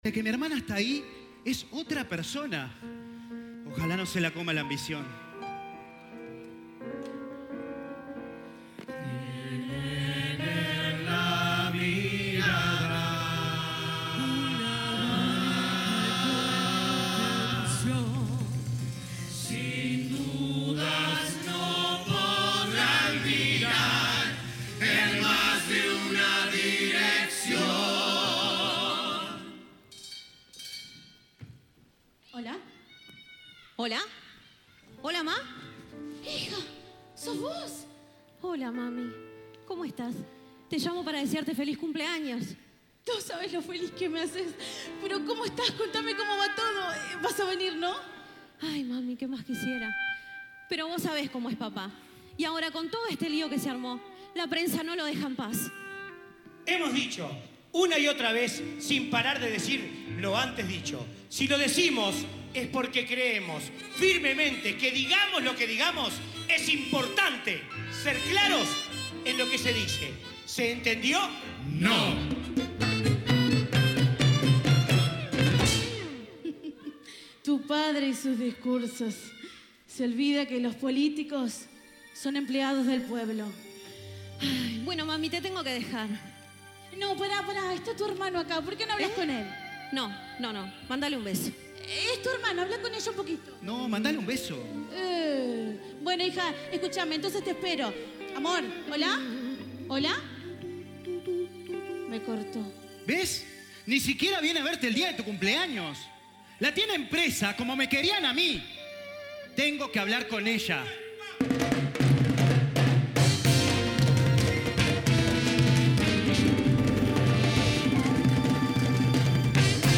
Composiciones y arreglos corales
Solistas
Coro